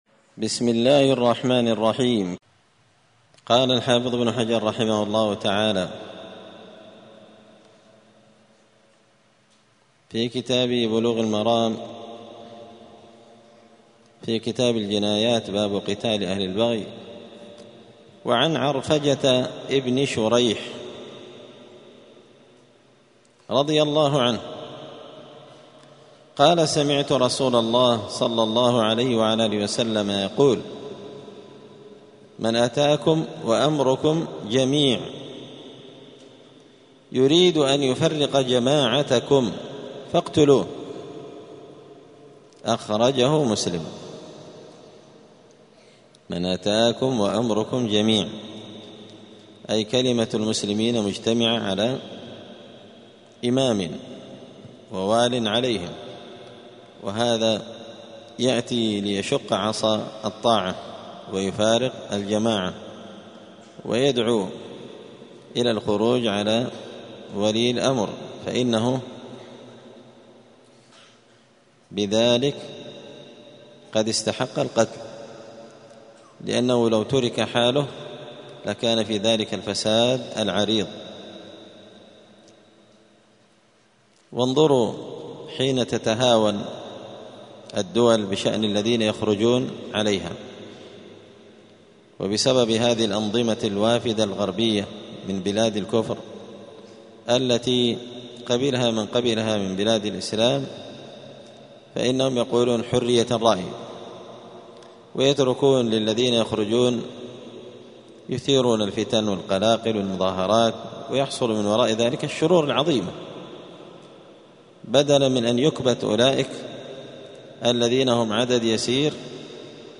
*الدرس الرابع والثلاثون (34) {باب قتال أهل البغي}*
دار الحديث السلفية بمسجد الفرقان قشن المهرة اليمن